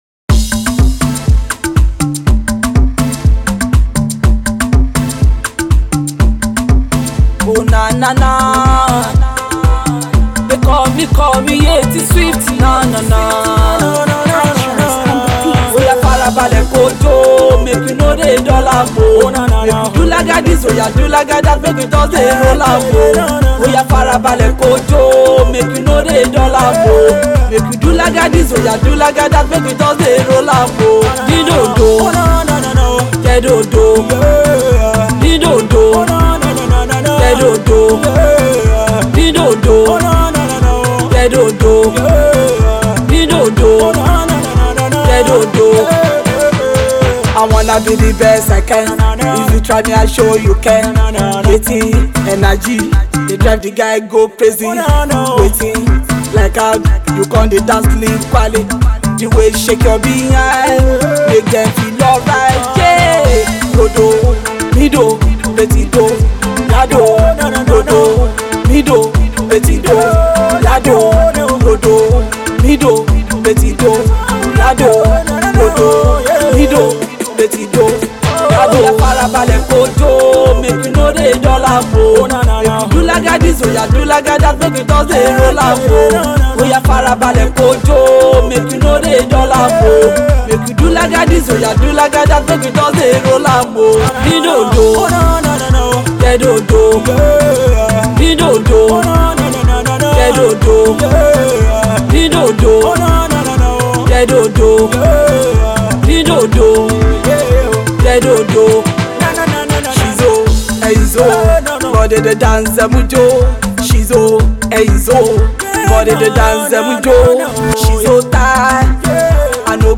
juju/hiphop